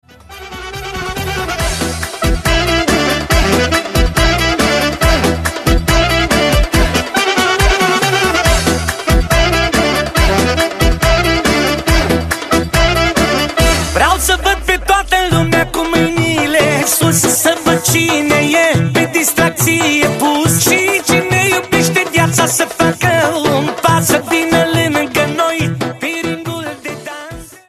Manele